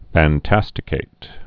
(făn-tăstĭ-kāt)